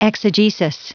Prononciation du mot exegesis en anglais (fichier audio)
Prononciation du mot : exegesis